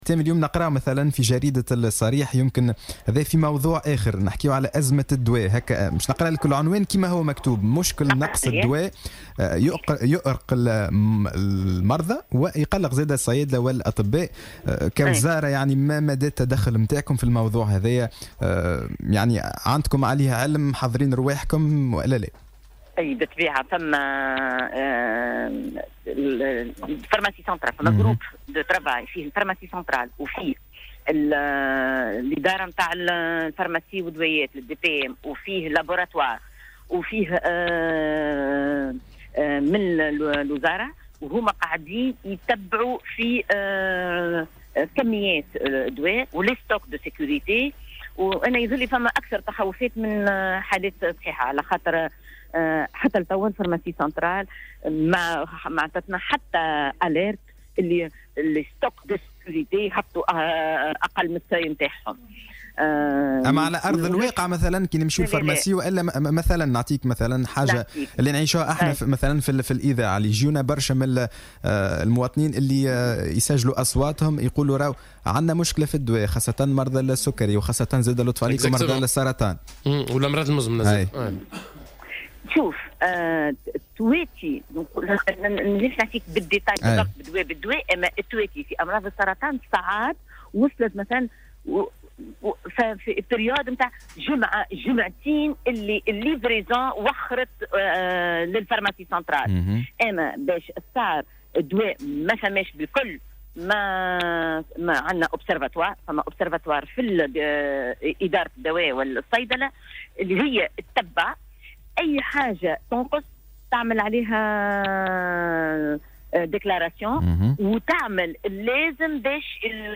أكدت المديرة العامة للصحة نبيهة البرصالي فلفول في مداخلة لها على الجوهرة "اف ام" صباح اليوم الثلاثاء 19 نوفمبر 2017 أن هناك فريق عمل متكون من الصيدلية العامة ومخابر الأدوية وممثلين عن الوزارة يعملون على ملف نقص الأدوية المسجل في تونس.